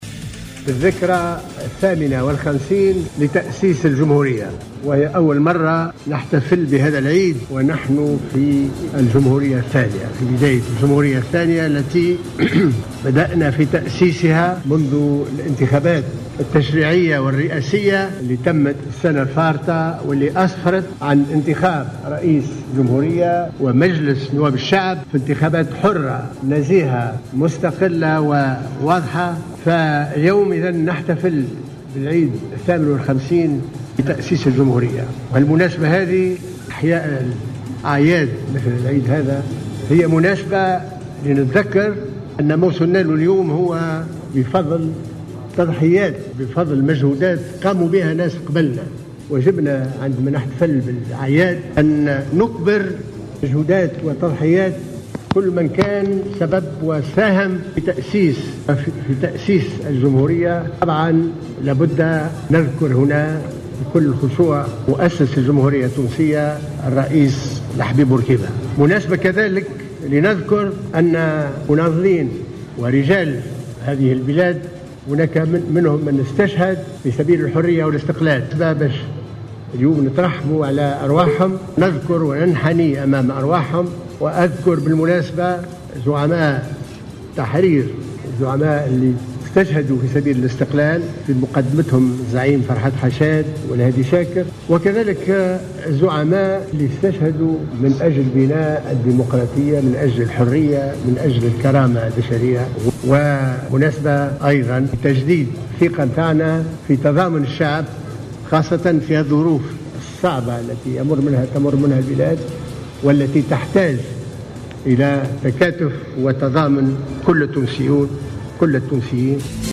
حفل استقبال بباردو بمشاركة الرؤساء الثلاثة بمناسبة الذكرى 58 لاعلان الجمهورية
انتظم، مساء اليوم السبت، بمقر مجلس نواب الشعب، حفل استقبال بمناسبة إحياء الذكرى 58 لاعلان الجمهورية، حضره، بالخصوص، رئيس الجمهورية،الباجي قائد السبسي، ورئيس مجلس نواب الشعب ،محمد الناصر، ورئيس الحكومة، الحبيب الصيد.